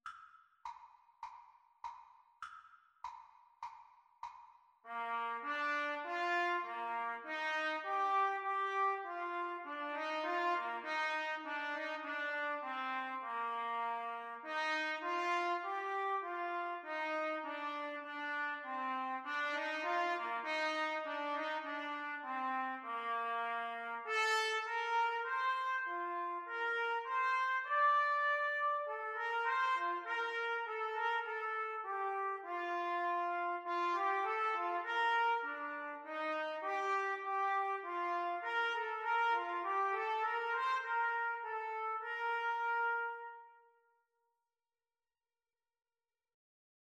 Bb major (Sounding Pitch) C major (Trumpet in Bb) (View more Bb major Music for Trumpet Duet )
4/4 (View more 4/4 Music)
Trumpet Duet  (View more Easy Trumpet Duet Music)